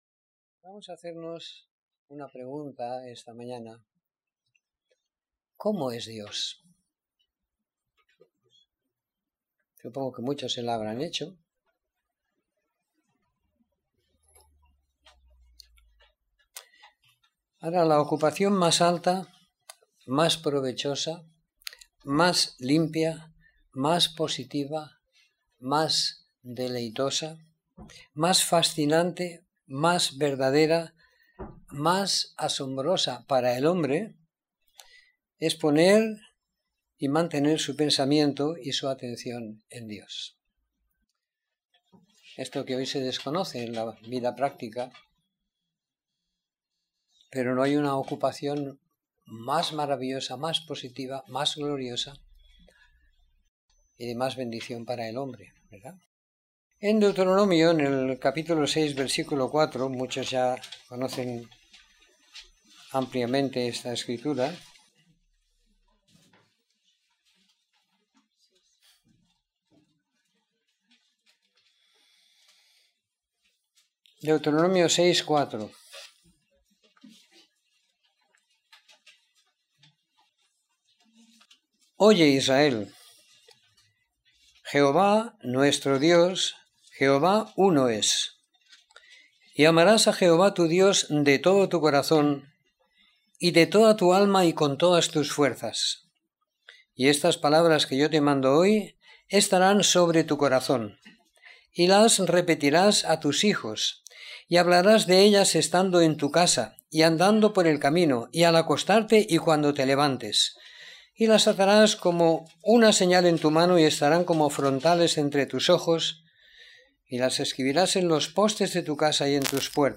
Reunión de Domingo por la Mañana